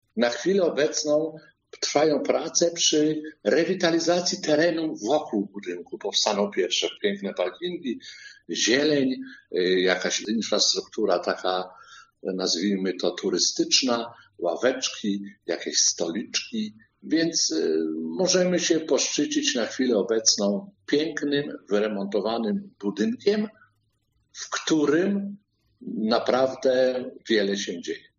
Burmistrz dodaje, że inwestycja jest już prawie zrealizowana.